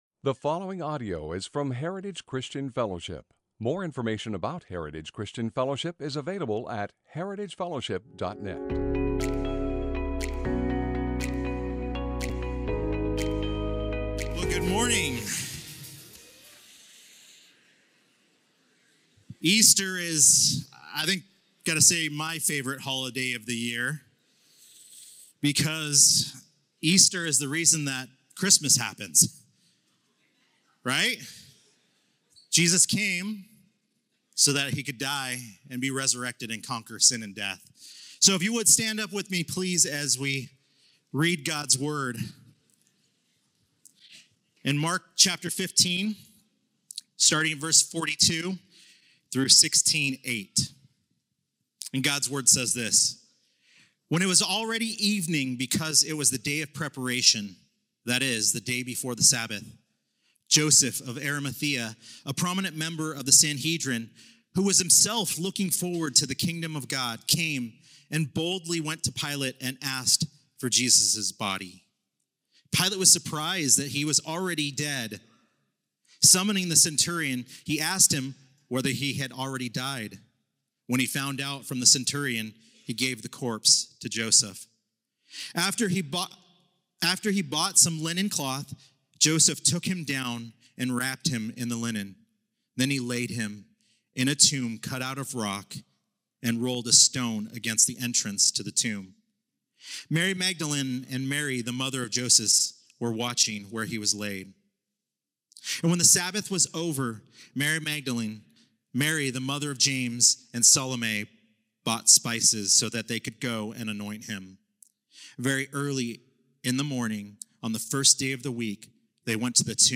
Sermons - Heritage Christian Fellowship | Of Medford, OR
Mark 15:42-16:8 Easter Sunday Heritage Christian Fellowship 2026